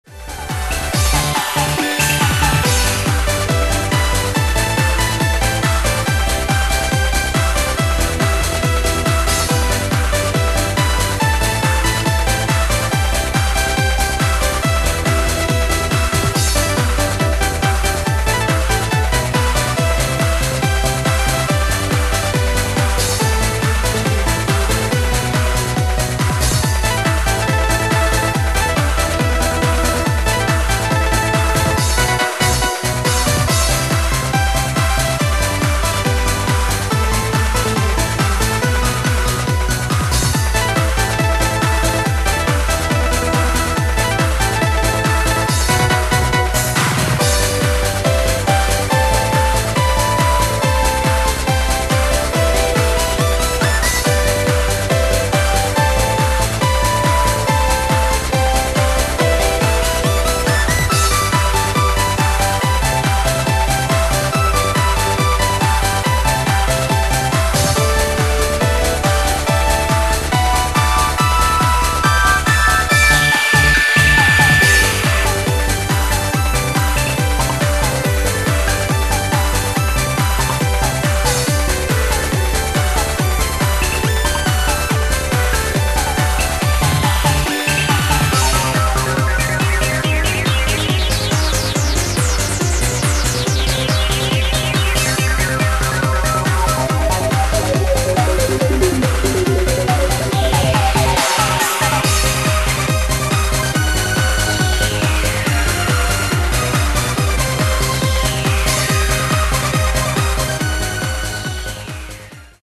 往来のファミコンVGMをドリームテクノでリメイク&アレンジ。
アコースティックな面が出てきた一作。